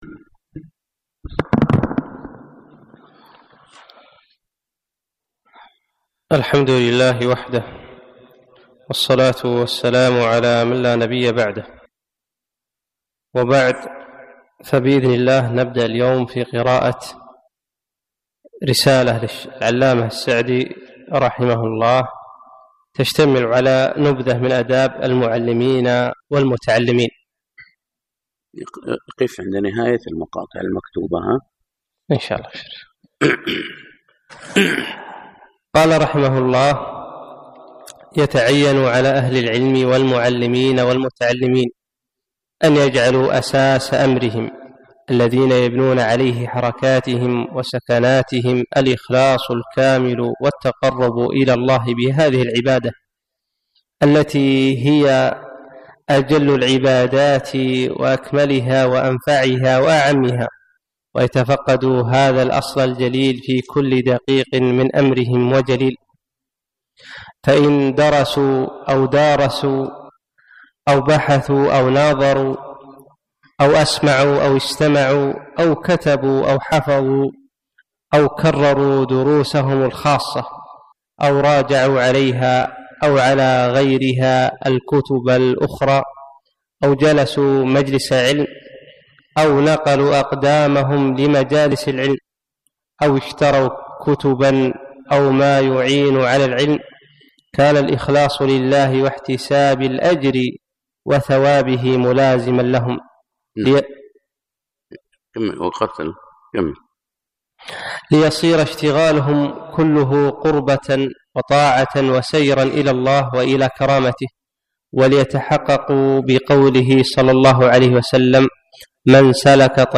فجر الأثنين 3 4 2017 مسجد صالح الكندري صباح السالم